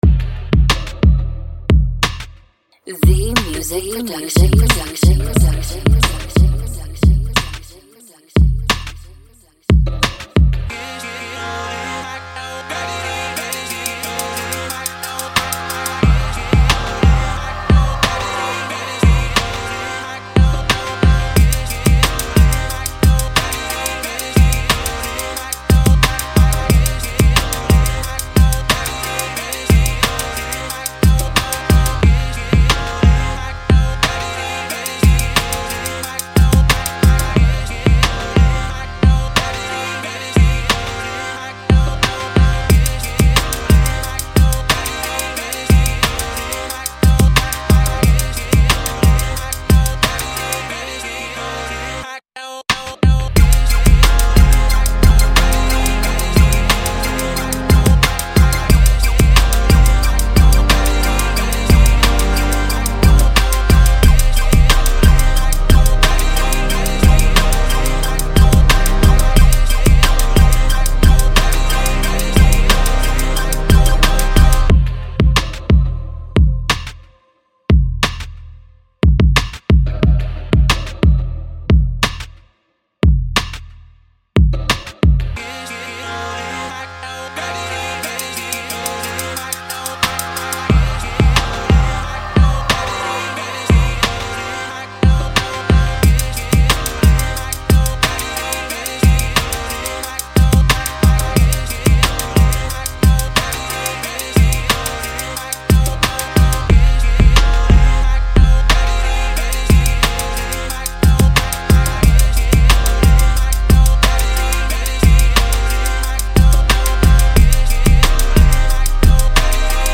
Electronic